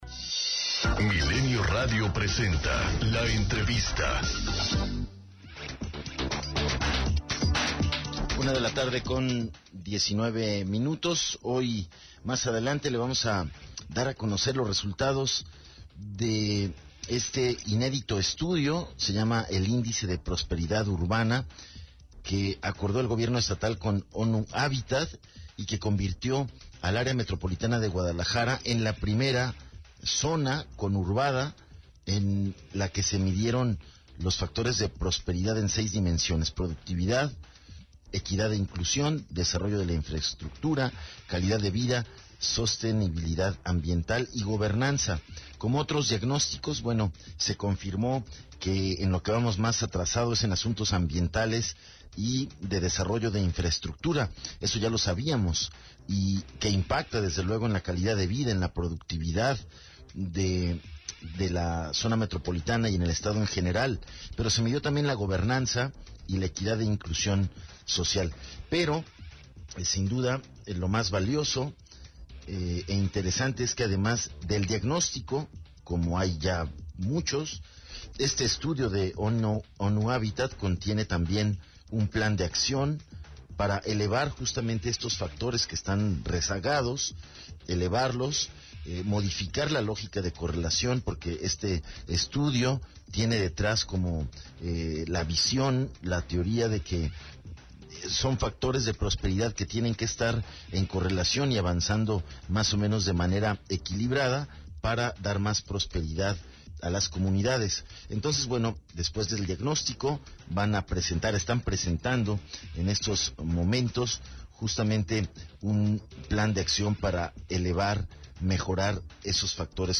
ENTREVISTA 090915